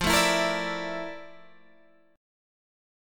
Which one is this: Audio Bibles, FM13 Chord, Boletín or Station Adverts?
FM13 Chord